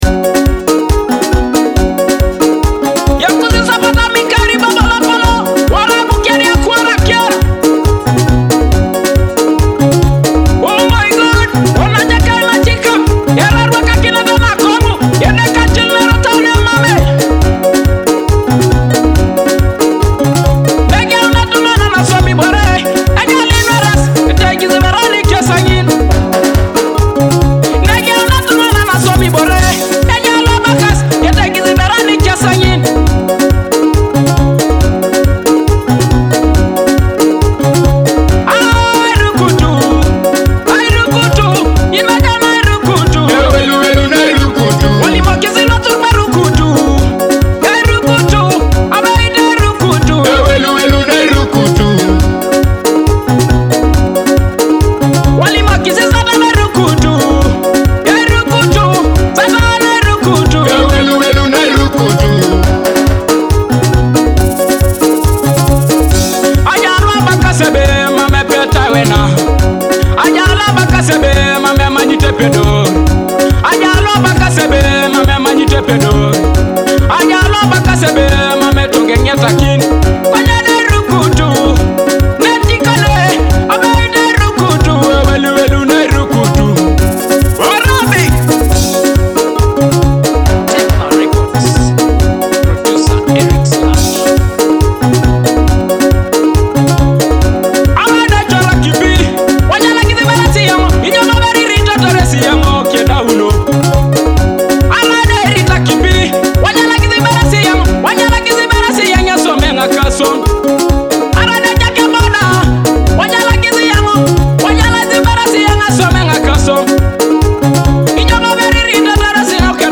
inspiring, joyful gospel